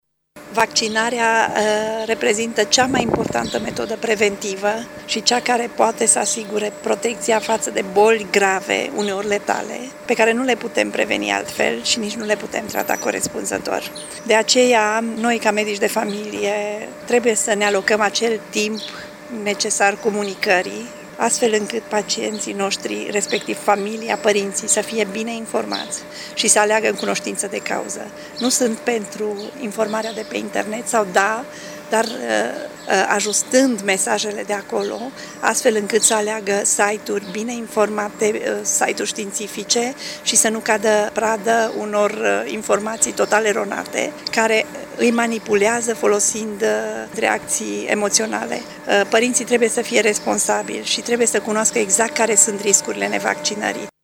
Colegiul Medicilor din Județul Mureș a organizat, ieri, simpozionul intitulat ”Vaccinarea – trecut, prezent și perspective – aspecte practice”, un eveniment dedicat medicilor de familie, medicilor pediatri, precum și celor de medicina muncii, de medicină internă și neuropsihiatrie infantilă.